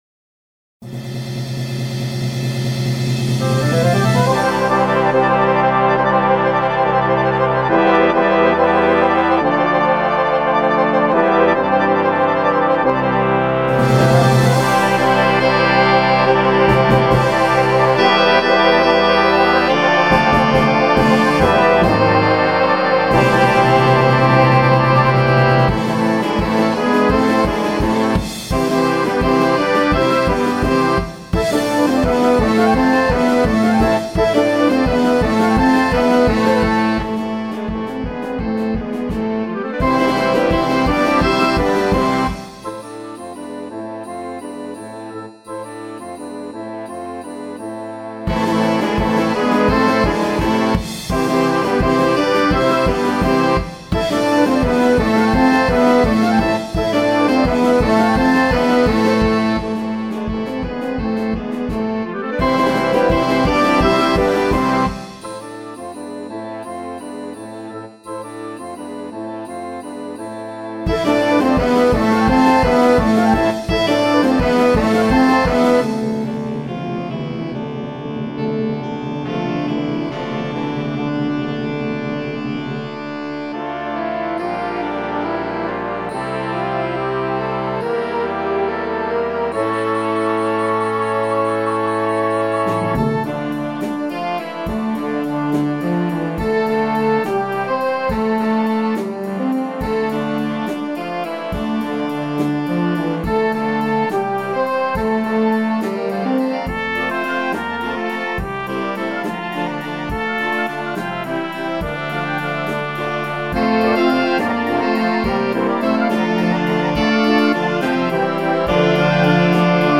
pro mládežnický dechový orchestr v Kolíně